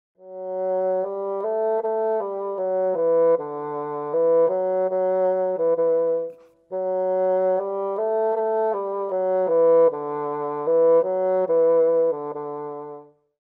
fagott.mp3